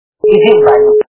» Звуки » Люди фразы » Голос - Иди в баню
При прослушивании Голос - Иди в баню качество понижено и присутствуют гудки.
Звук Голос - Иди в баню